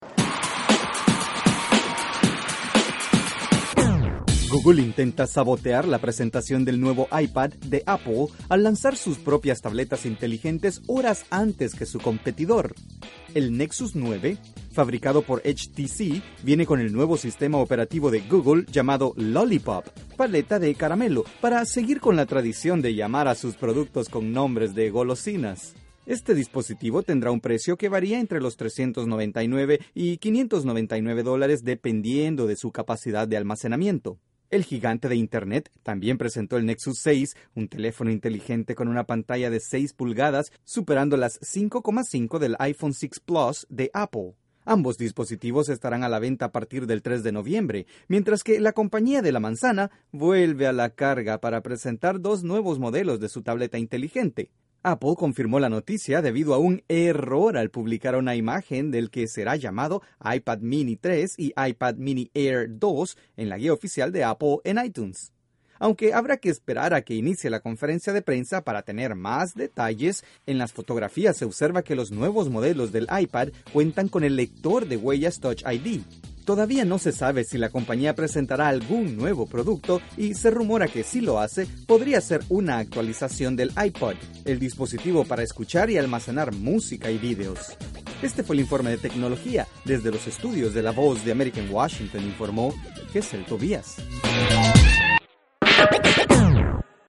La gigante del internet, que también se dedica al desarrollo de aparatos móviles, presentó su nueva tableta Nexus 9, un día antes de que Apple presente una nueva versión de su iPad. Desde los estudios de la Voz de América en Washington informa